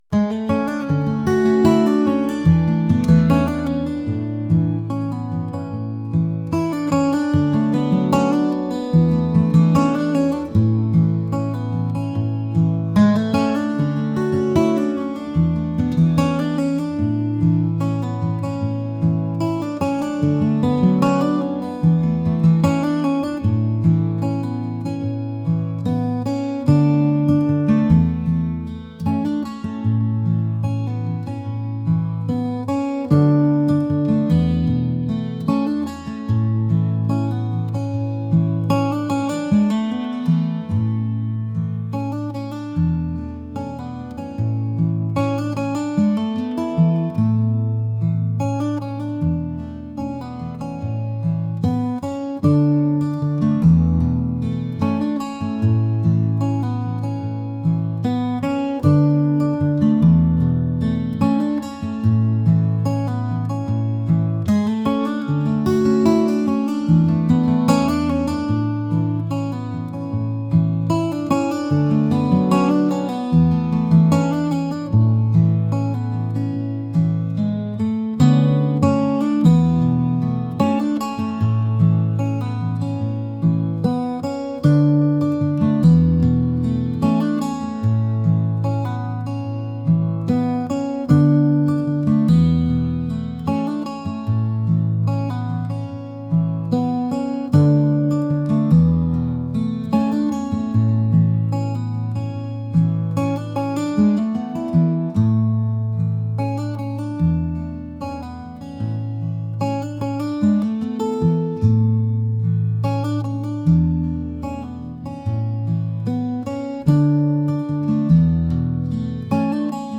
acoustic | folk